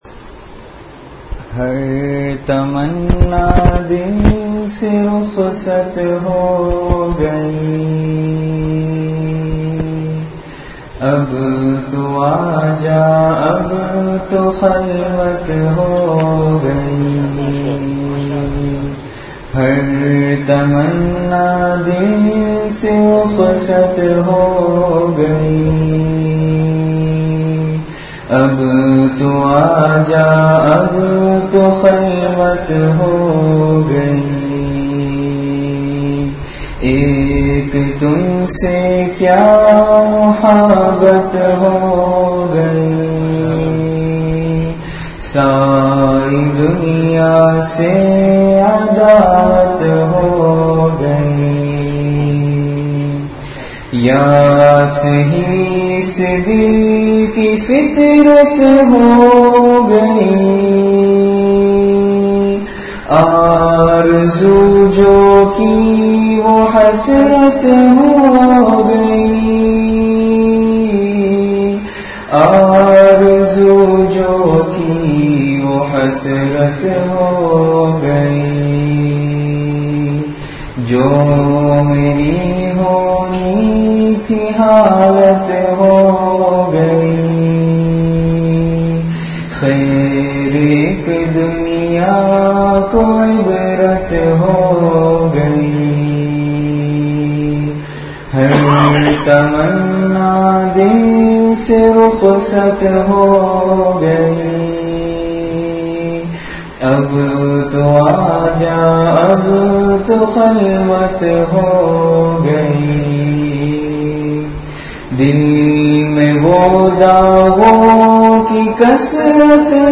Delivered at Jamia Masjid Bait-ul-Mukkaram, Karachi.
Majlis-e-Zikr · Jamia Masjid Bait-ul-Mukkaram, Karachi